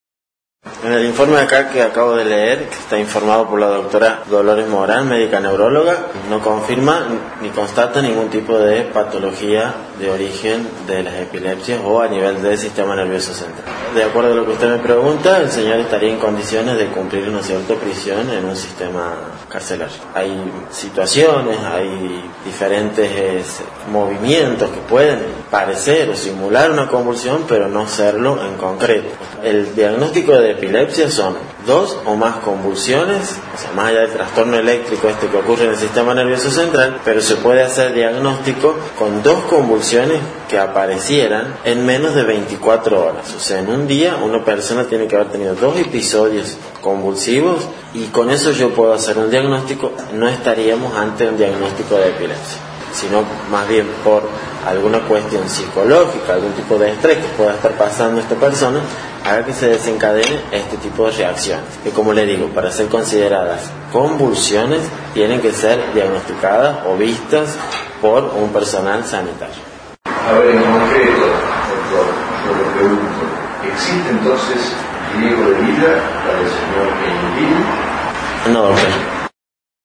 Escuchá la participación del médico forense en esta audiencia.